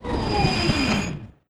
train_stop2.wav